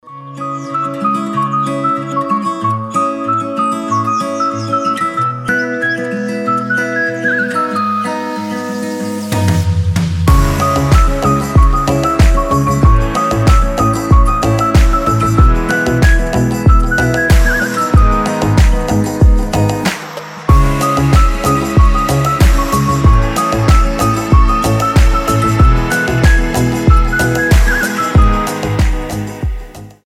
инструментальные
гитара
chillout , пение птиц
chill house